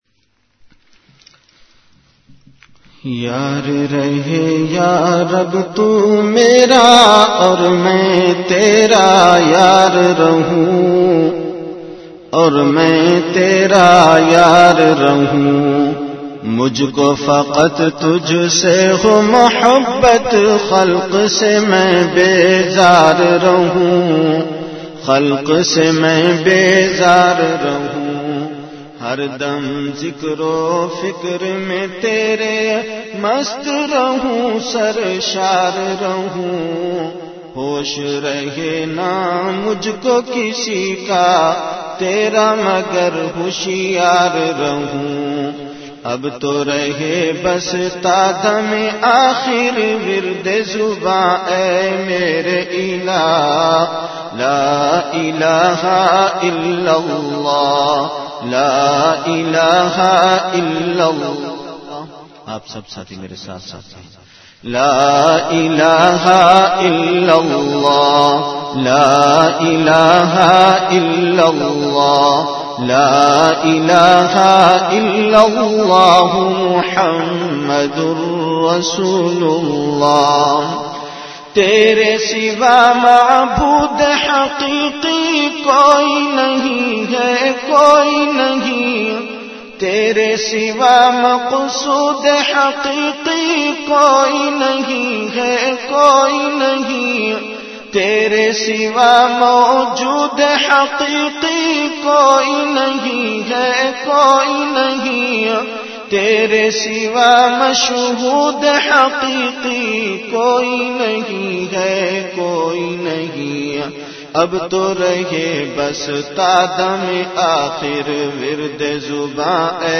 Delivered at Home.